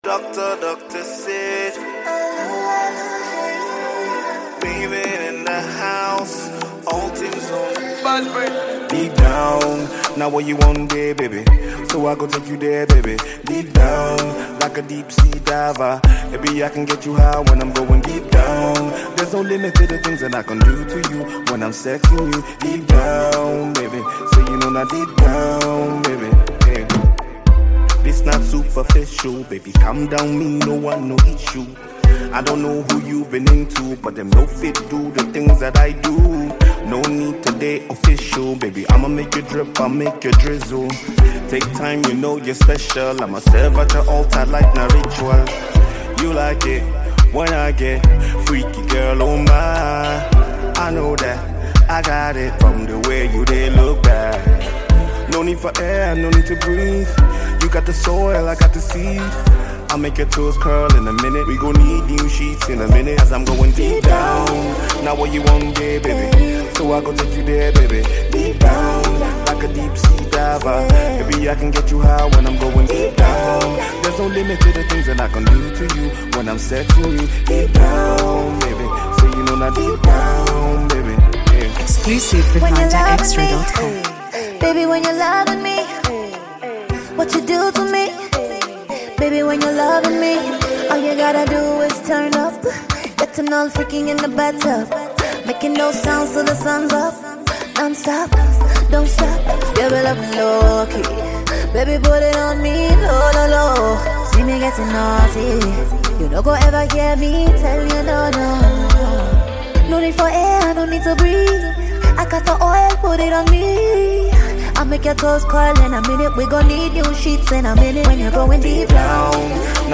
When two Afro Singer meets then you Should expect a banger